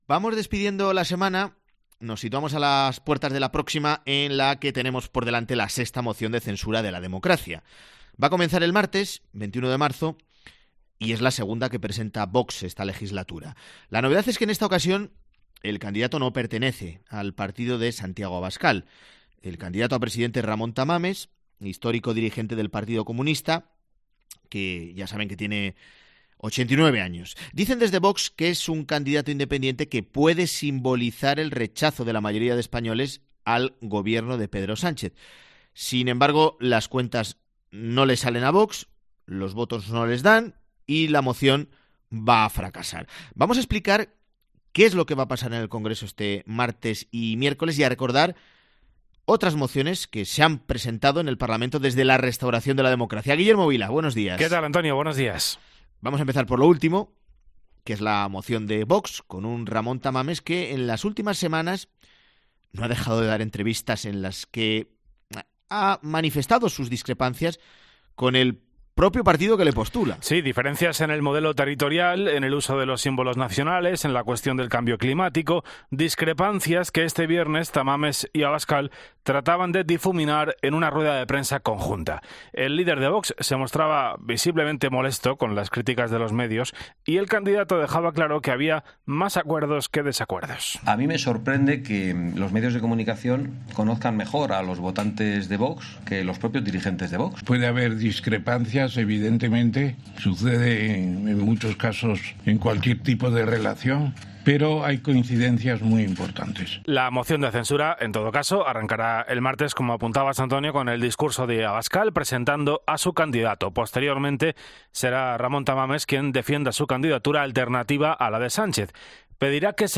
En La Mañana de Fin de Semana de COPE analizamos las claves de la moción de censura al Gobierno, con los mejores sonidos de las cinco anteriores en el...